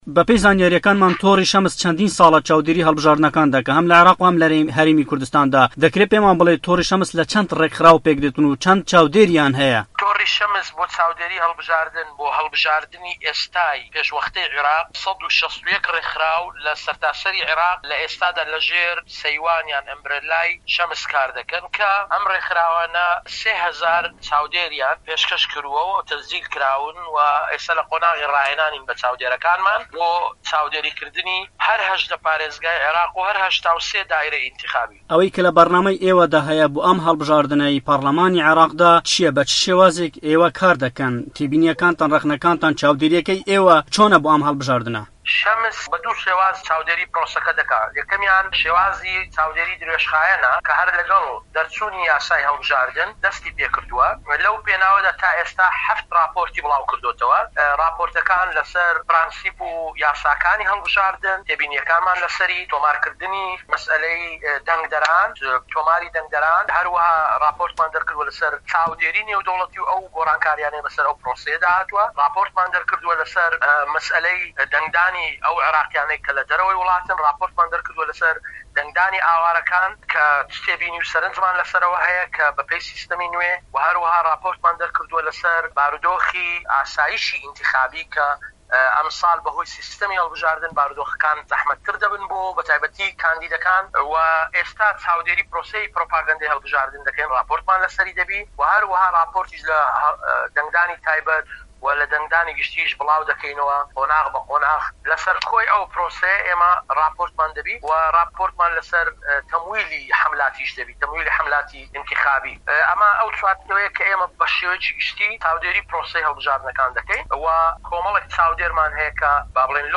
لەمیانەی وتووێژەکەدا لەگەڵ دەنگی ئەمەریکا، سەبارەت بەوەی کە دەگوترێت لە زۆر بنکەی دەنگدان تەنانەت چاودێر و نوێنەری هەندێک لایەن و رەوتی سیاسیشی تێیدا نەبینراون بۆ چاودێریکردنی پرۆسەی هەڵبژاردنەکانی ڕابردوو نەک بە تەنها تۆڕی شەمس، ئایا بۆ ئـەم هەڵبژاردنەی ئەنجومەنی نوێنەرانی عێراق لە هەموو بنکەکانی دەنگدان تۆڕی شەمس چاودێری دەبێت واتا لەو بنکانەشی کە لە گوند و کۆمەڵگا دوورەکان دادەنرێن ؟